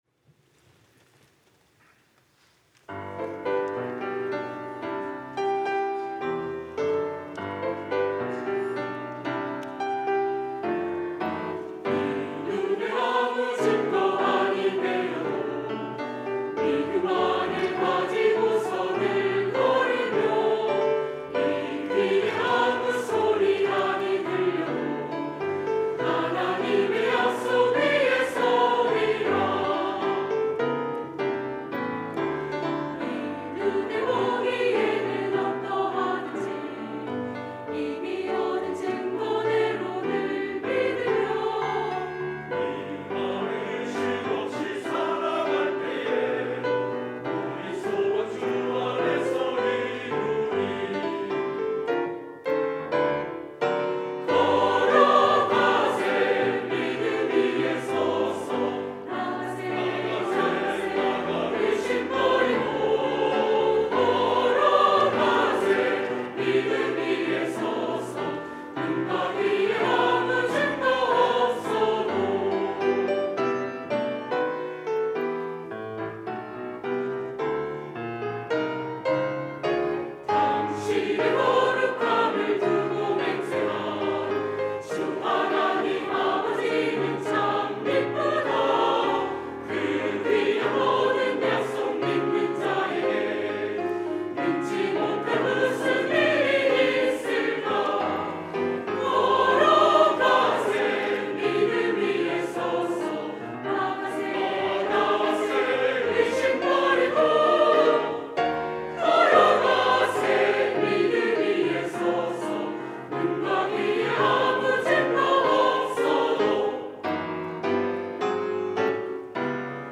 할렐루야(주일2부) - 이 눈에 아무 증거 아니 뵈어도
찬양대